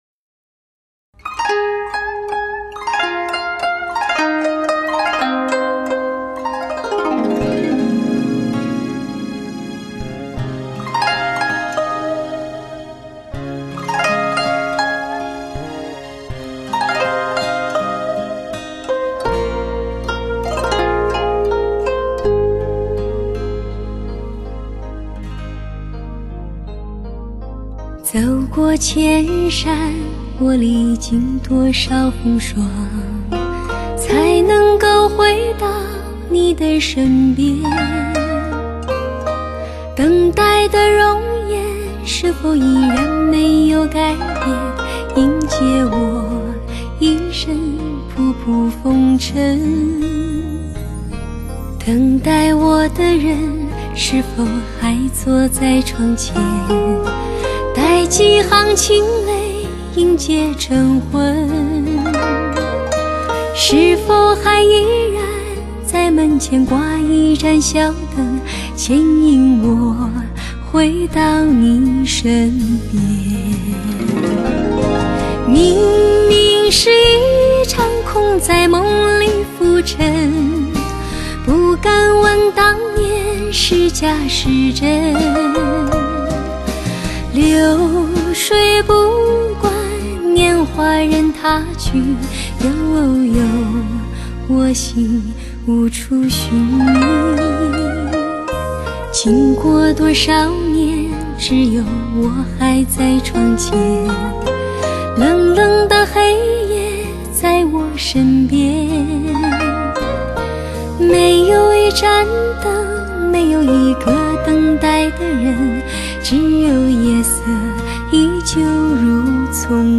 不错的音乐，呵呵，也算比较清纯的歌声
清纯的歌声